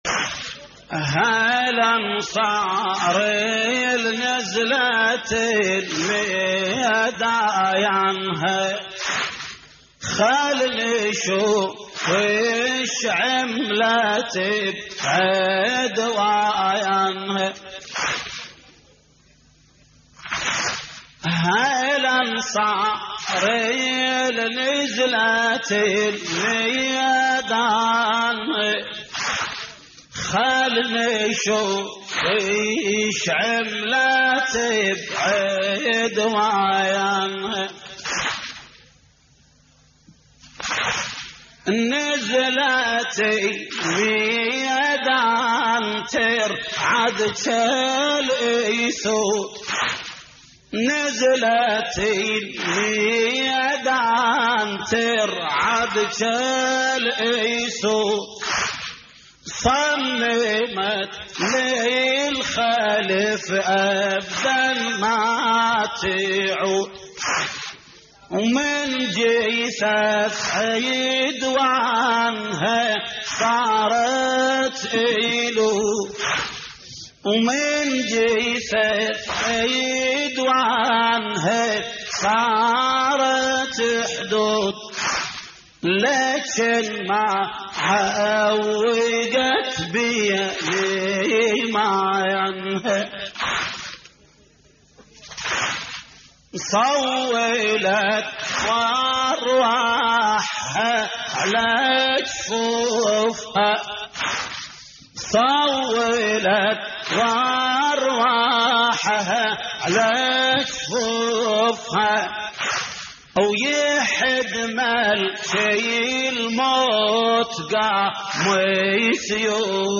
موشح